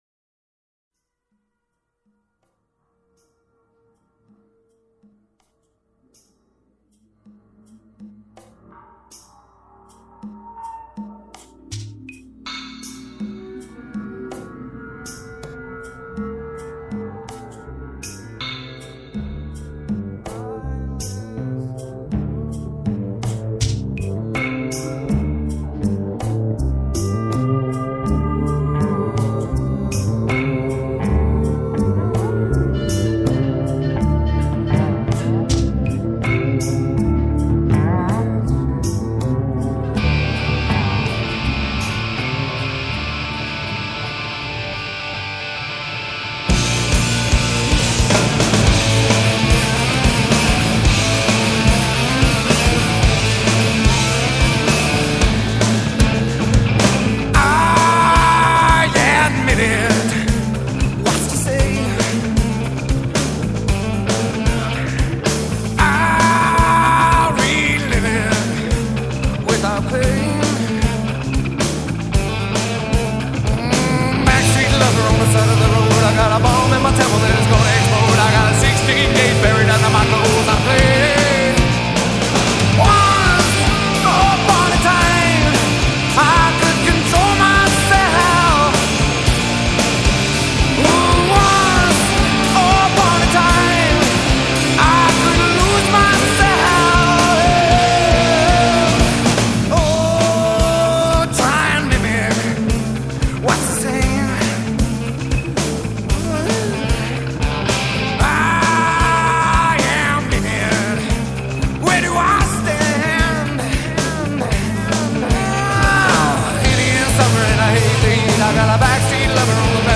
Grunge Rock, Alternative Rock